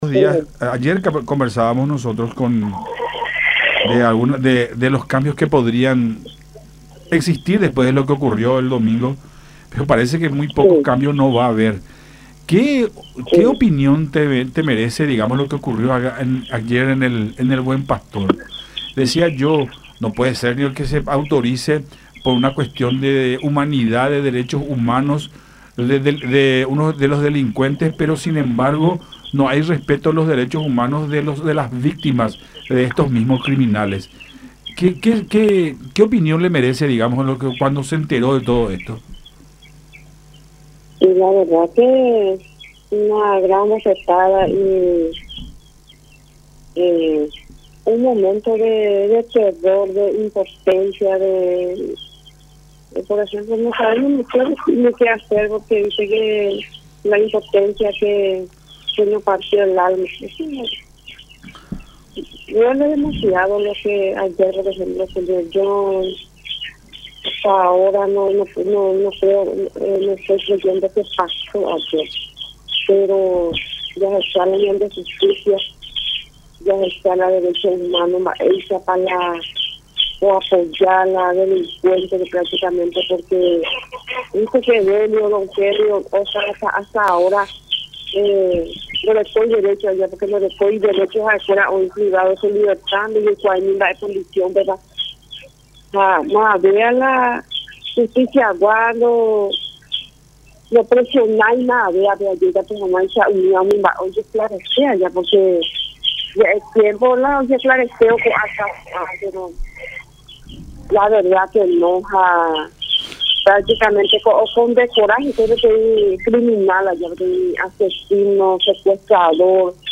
en diálogo con Nuestra Mañana a través de Unión TV y radio La Unión.